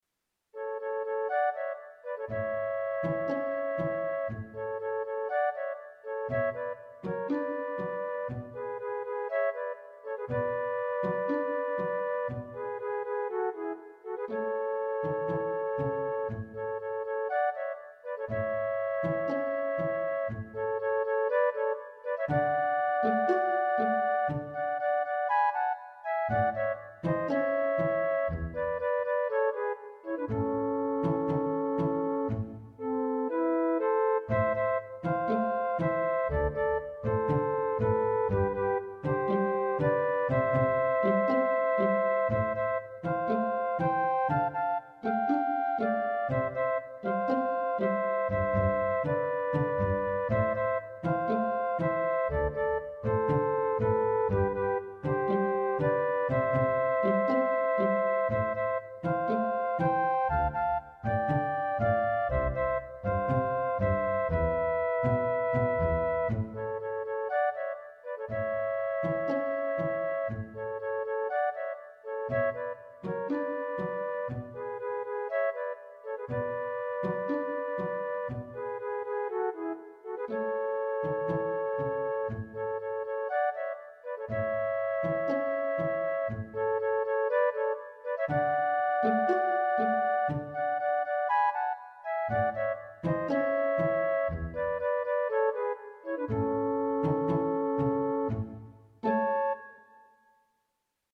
Orchestral Music Examples
for 2 flutes and pizzicato strings